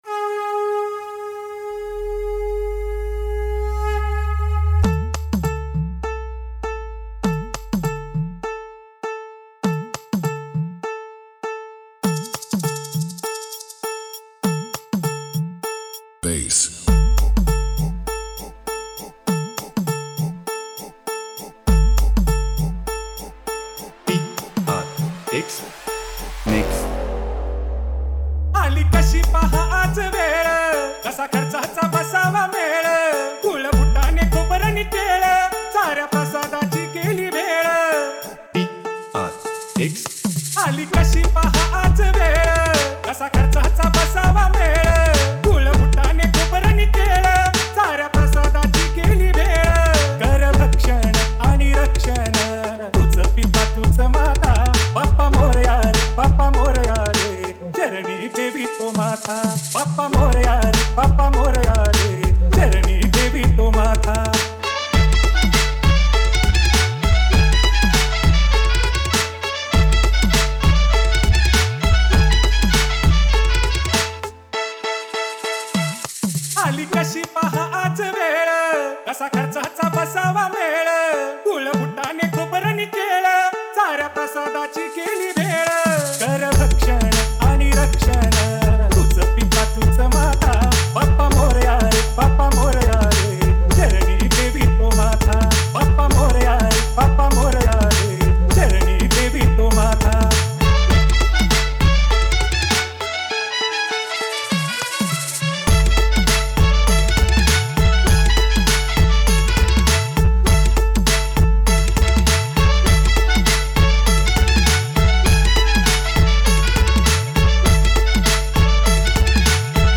Category: MARATHI DJ